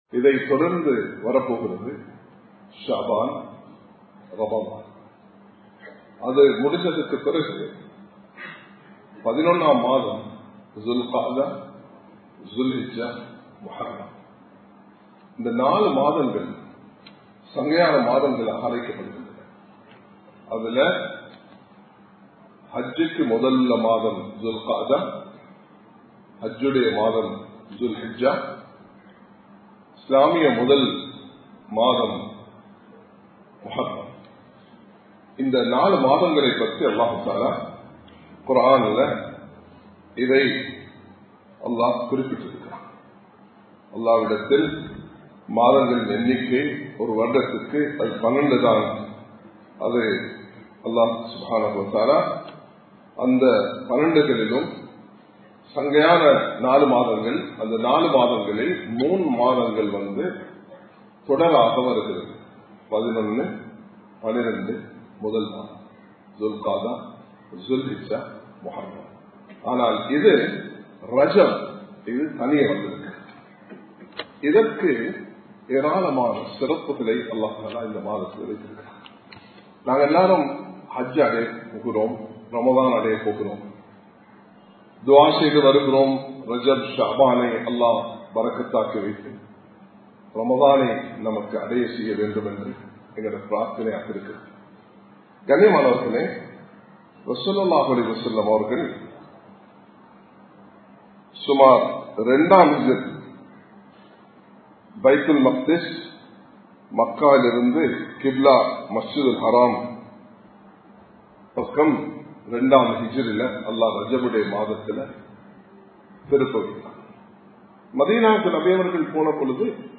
ரஜப் மாதத்தின் சிறப்புகள் | Audio Bayans | All Ceylon Muslim Youth Community | Addalaichenai
Samman Kottu Jumua Masjith (Red Masjith)